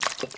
Potion Drink (1).wav